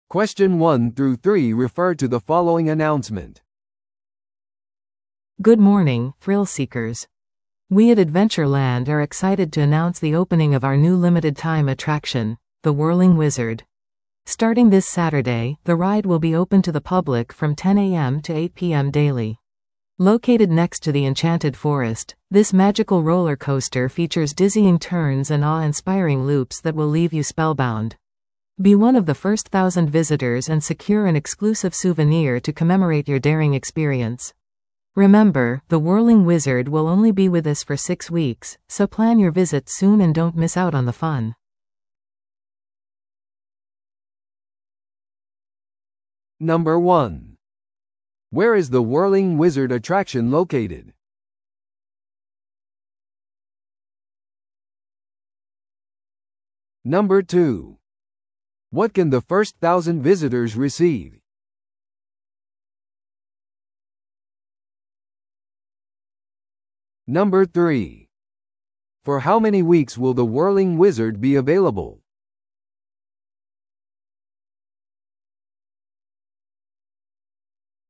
TOEICⓇ対策 Part 4｜限定アトラクションの告知 – 音声付き No.106